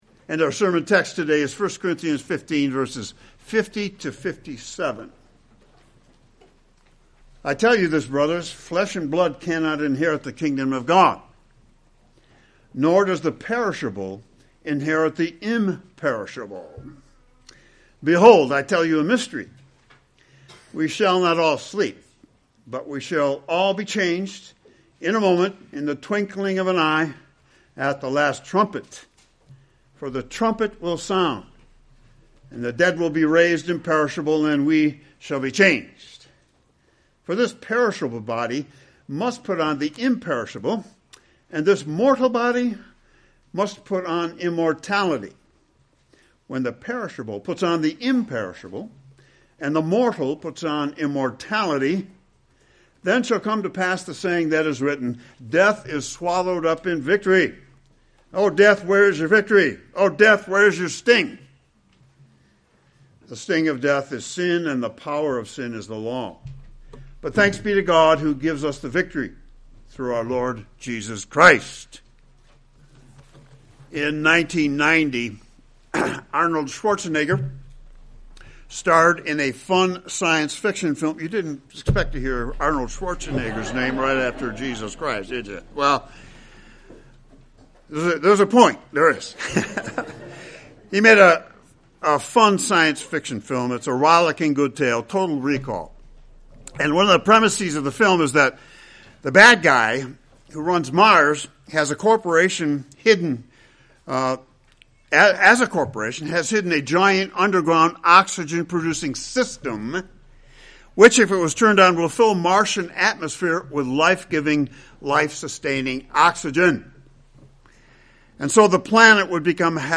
New Geneva OPC - Sermons - Lent and Easter